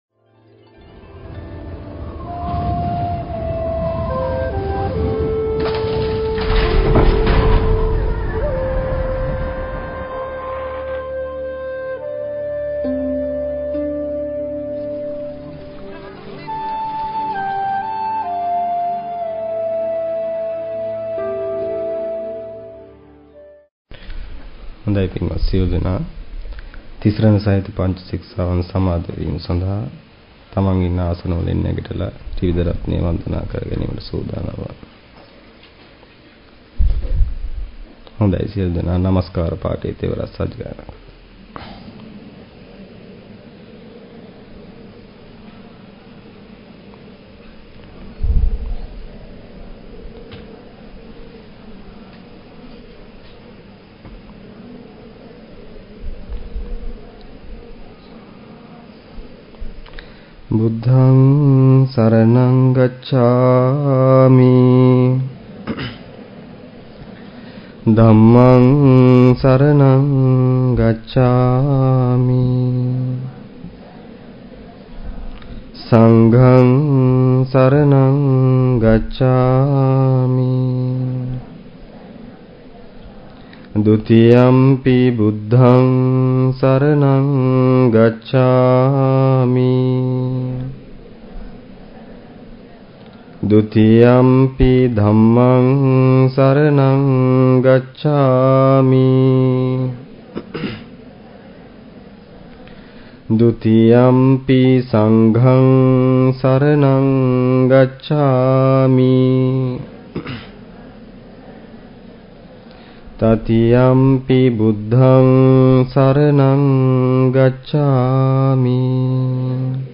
Sermon | JETHAVANARAMA